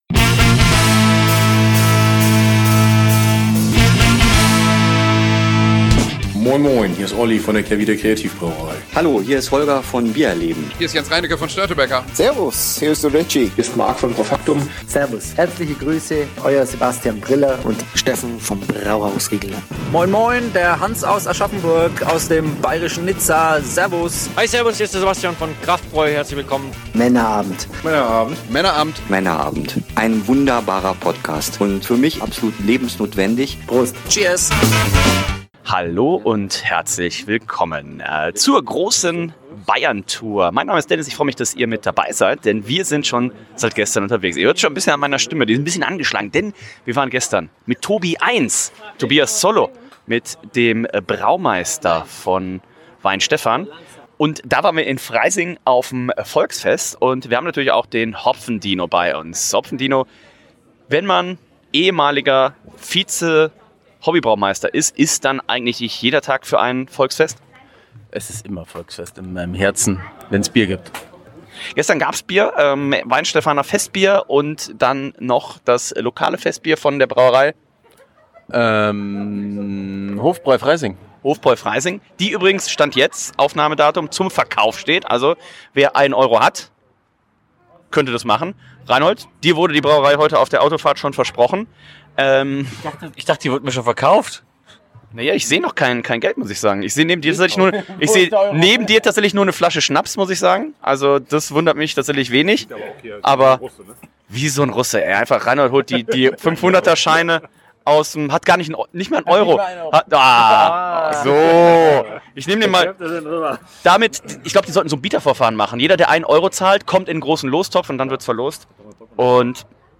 Danach melden wir uns direkt von der Drinktec Messe in München – DEM Branchentreffpunkt für Bierbegeisterte und Profis.